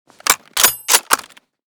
mosin_unjam.ogg.bak